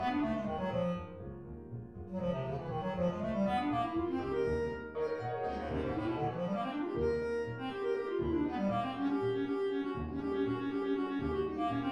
A Monodrama for Bass/baritone
The ensemble has just taken over from the singer’s line “…my wicked eyes.” to begin an extended bass clarinet solo.